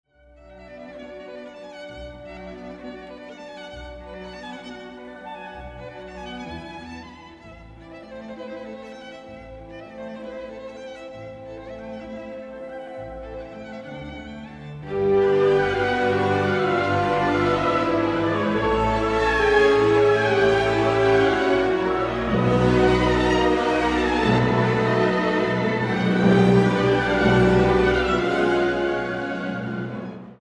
Symphony Orchestra of India
This young, energetic orchestra with a growing reputation currently includes members from more than 20 countries who reside in Mumbai and it consistently attracts the best conductors and soloists from around the world.